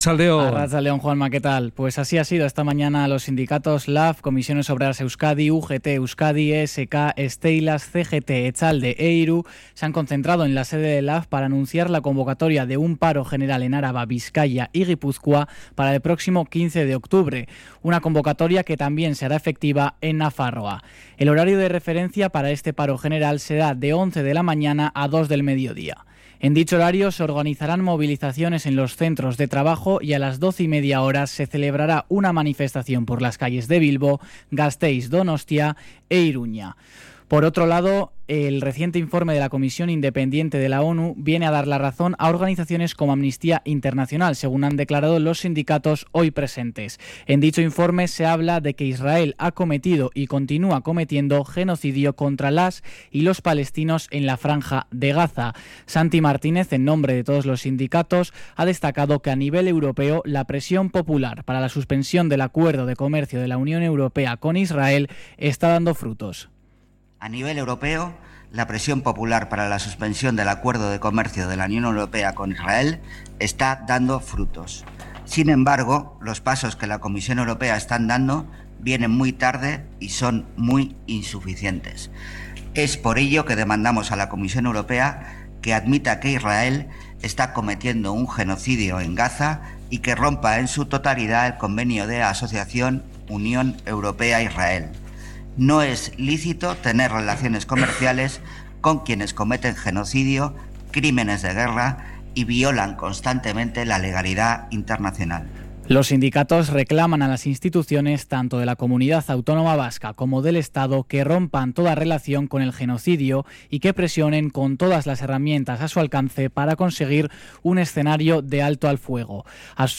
cRONICA-pARO-GENERAL-.mp3